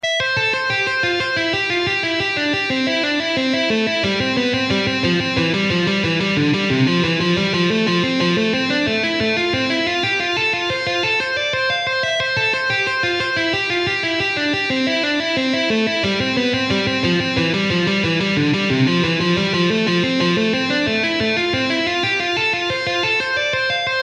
Click the following links to view some examples of rock guitar technical studies for intermediate and advanced students.
I IV Pivot Tone Study
I-IV-Pivot-Tone-Study.mp3